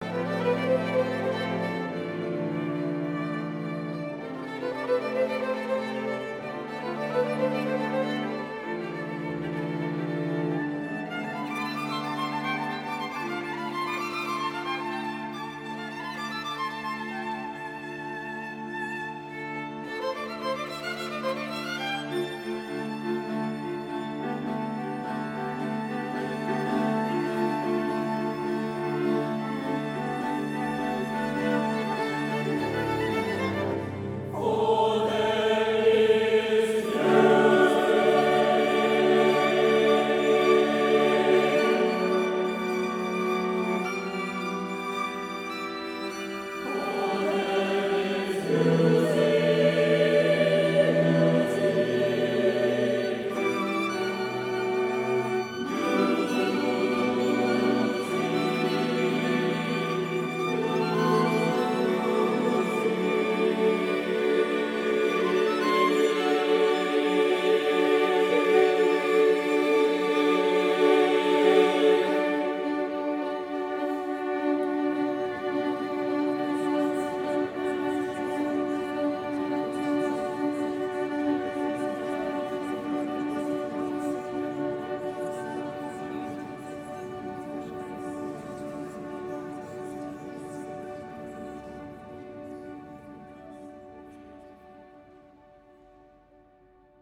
for SATB Choir, solo alto and string quartet